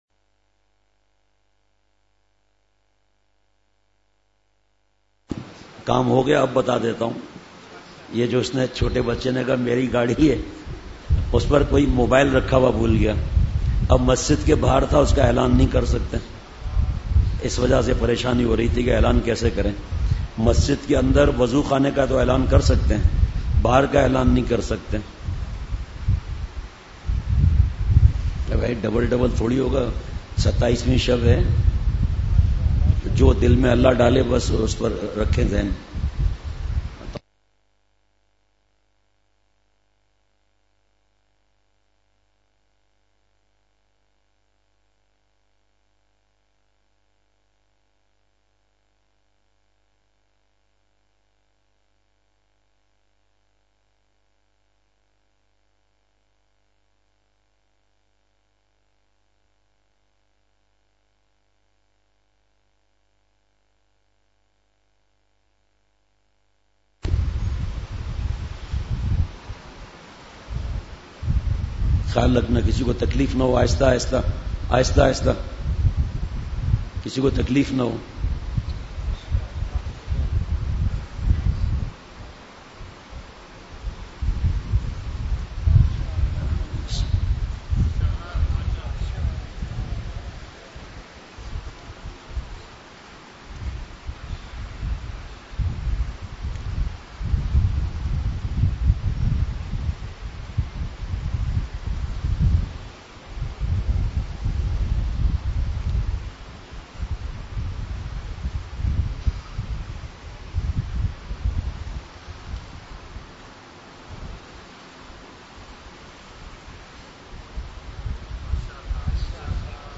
مجلس۲جولائی ۲۰۱۶ء:شبِ قدر کی فضیلت پر درد بھرا بیان !
Majlis 2 July 2016_Shab-e-Qadr ke Fazelat pr Dard Bhara Bayan.mp3